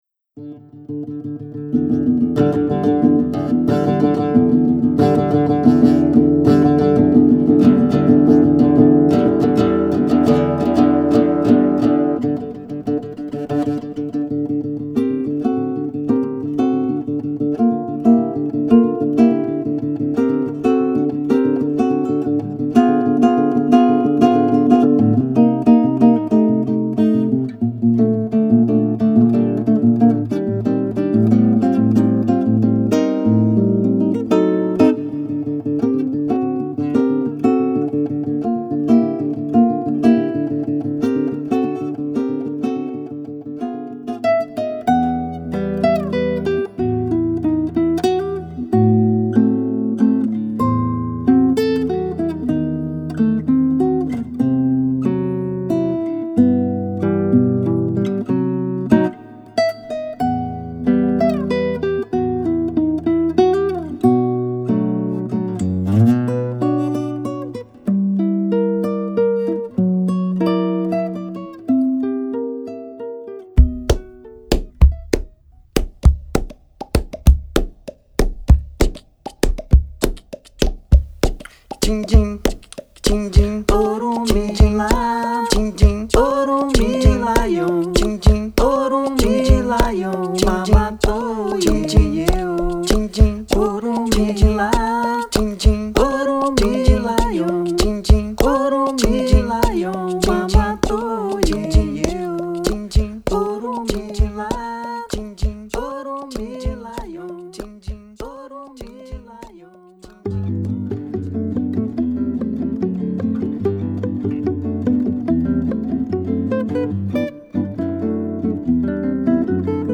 Brésil / Choro / Capoeira
violão, cavaquinho, musique corporelle